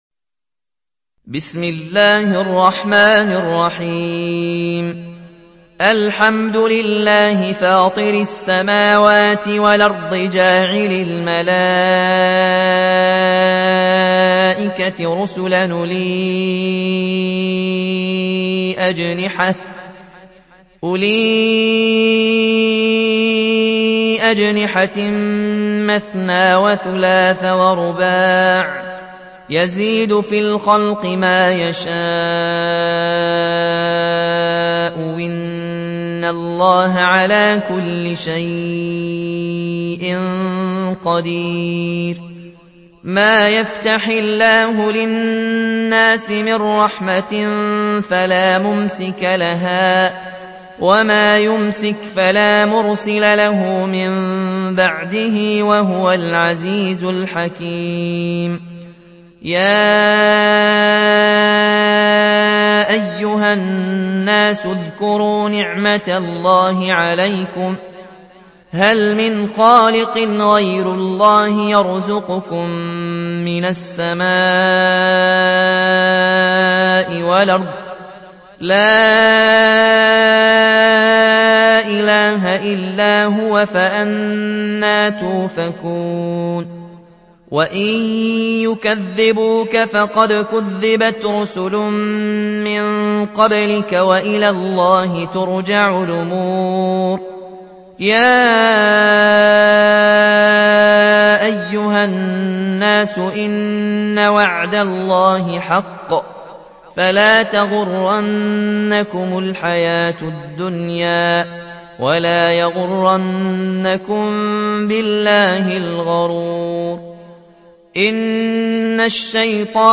Une récitation touchante et belle des versets coraniques par la narration Warsh An Nafi.
Murattal